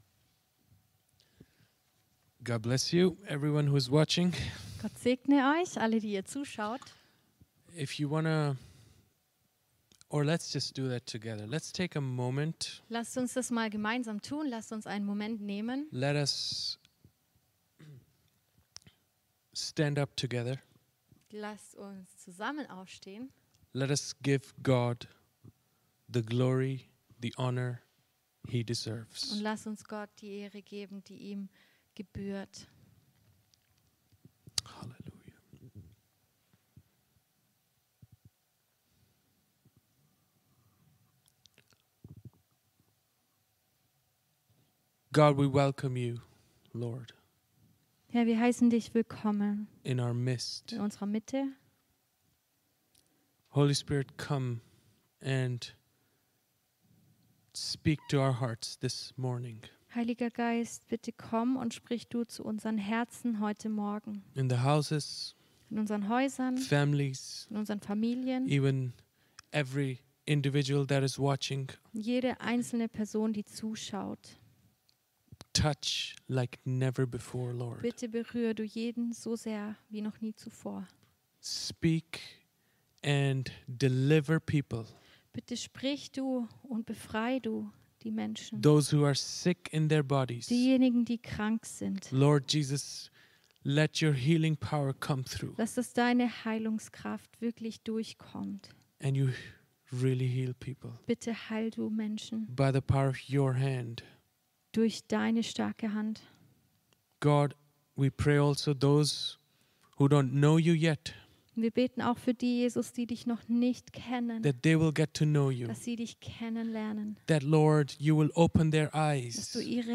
Title: Being a disciple of Jesus and the Cost of it - Predigt
- Sprache: Englisch mit deutscher Übersetzung